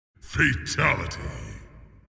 fatality-mortal-kombat-sound-effect-hd.mp3
KFAazwCzXIE_fatality-mortal-kombat-sound-effect-hd.mp3